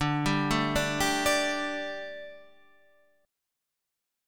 Dsus4 chord